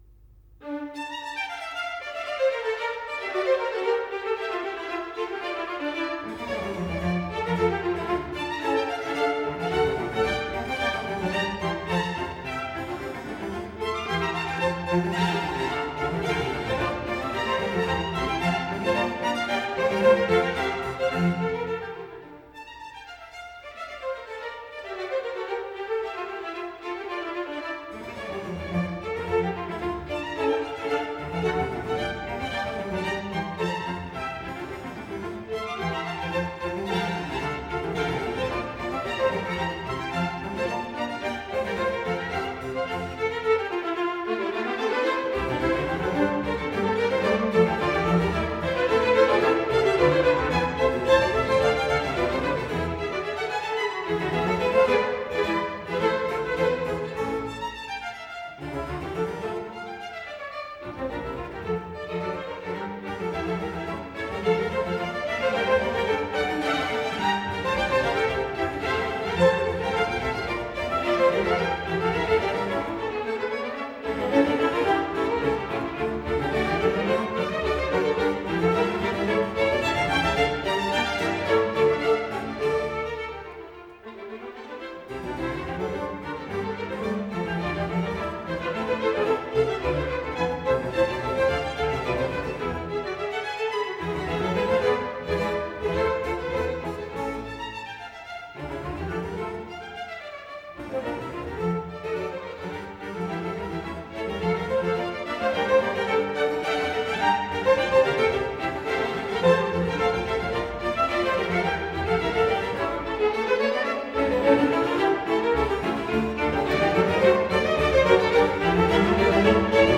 10 - Concerto Grosso Op.6 No.10 in D minor - Allegro